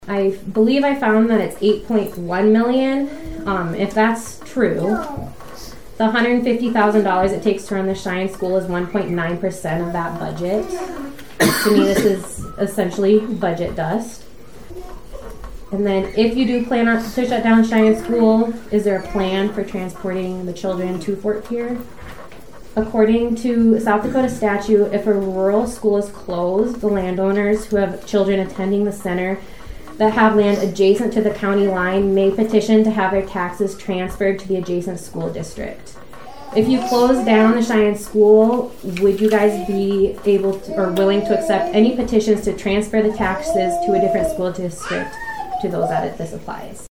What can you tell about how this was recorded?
During the public comment portion, about a dozen residents– all of them opposed– addressed the possibility of closing the school.